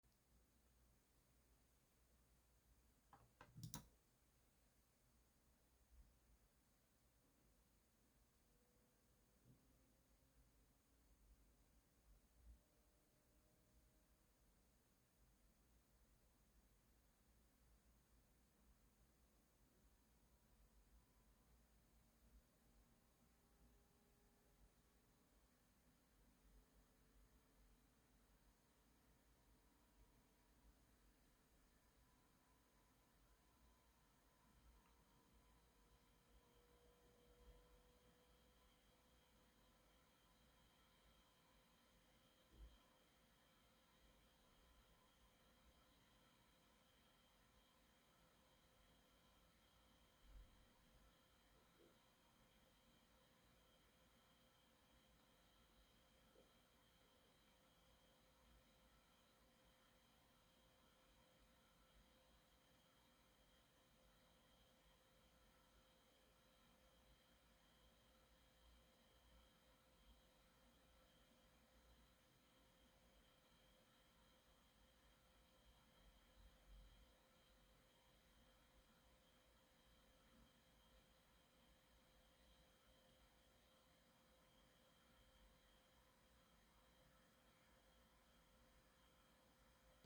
Unter Dauervolllast wird das System hörbar, mit 35 dB(A) aber auf vergleichsweise geringem Geräuschlevel.
Der Lüfter fährt dabei bei zunehmender Last langsam hoch und geht nach Abschluss der Rechenlast schnell wieder zurück zum sehr leisen Betrieb.
Audio-Impressionen des Kühlsystems
Die Aufnahme erfolgte mit 40 Zentimetern Abstand zur Gehäusefront aus dem Leerlauf in einen CPU-Volllast-Benchmark.